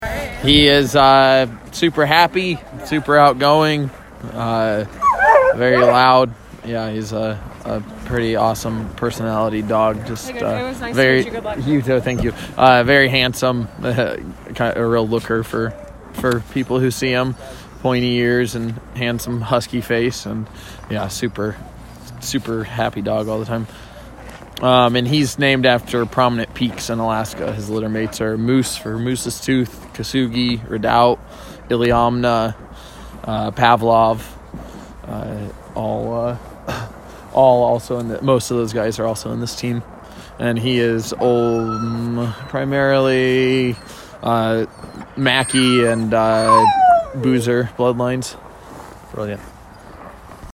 Current Location: Iditarod Headquarters, Wasilla, AK